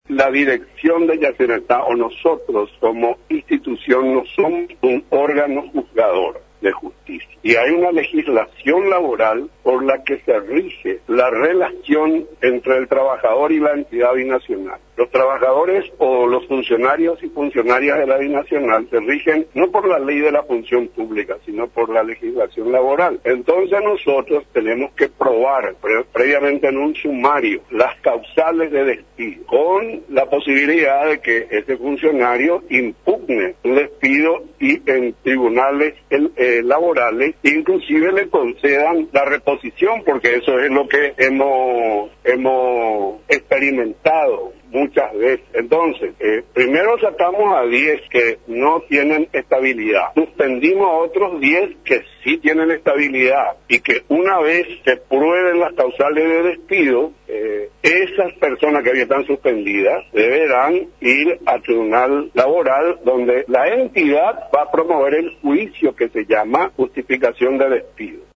El director paraguayo de Yacyretá, Nicanor Duarte Frutos; habló de legislaciones laborales que abordan causales de despido de funcionarios.